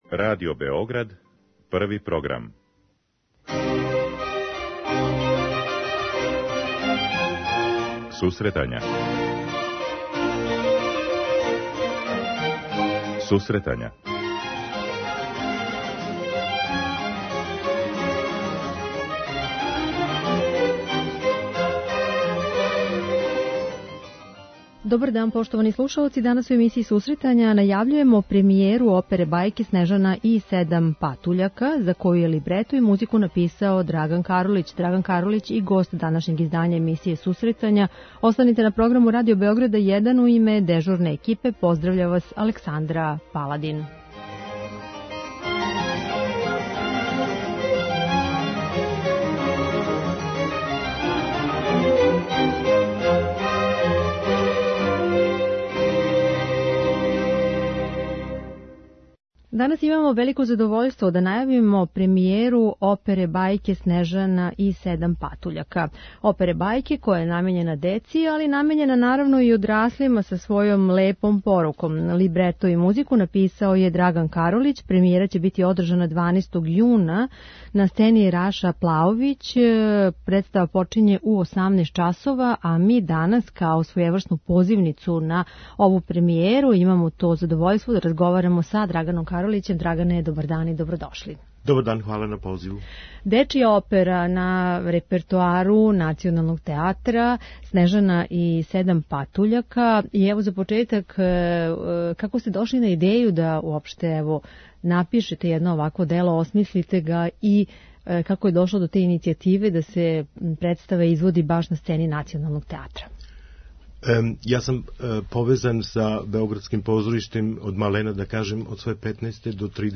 Обе представе почињу у 18 часова. преузми : 10.06 MB Сусретања Autor: Музичка редакција Емисија за оне који воле уметничку музику.
Кроз емисију биће емитована и одабрана дела овог композитора, а слушаоци ће бити у прилици да га упознају и као извођача, који је значајни део своје каријере посветио раној музици.